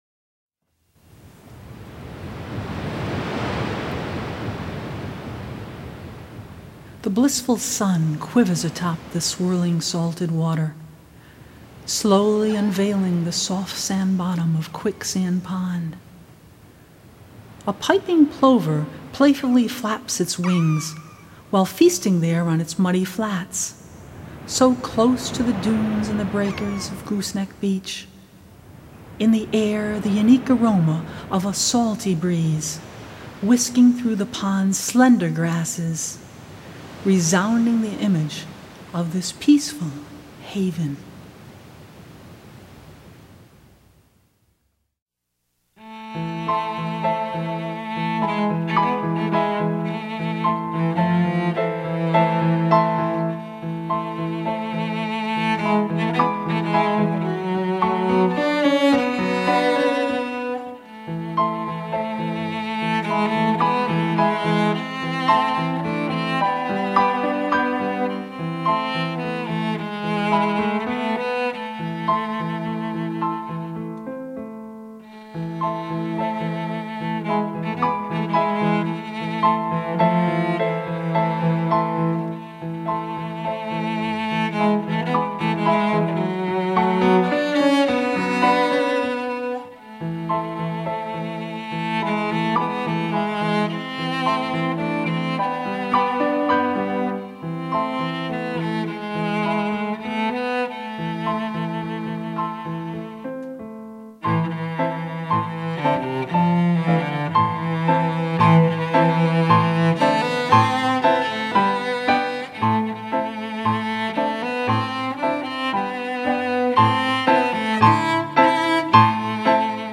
Chamber Ensemble